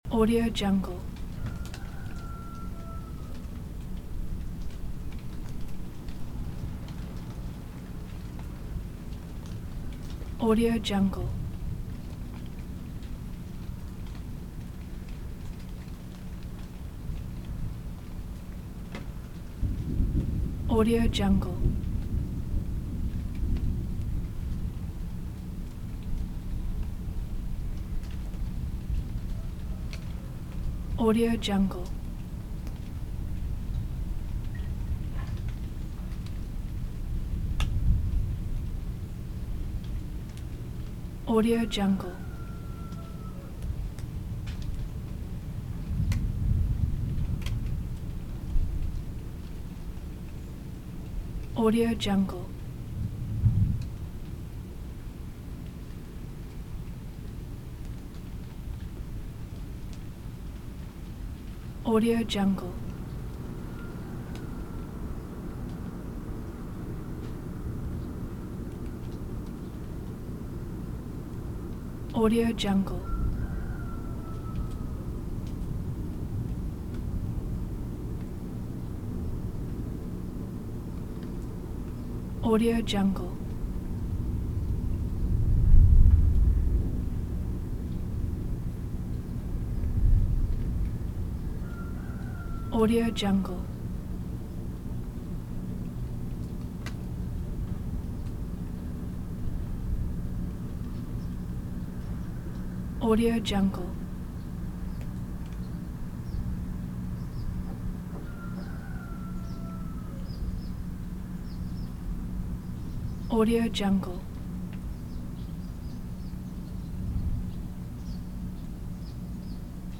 دانلود افکت صوتی رعد و برق و طوفان در فاصله بسیار دور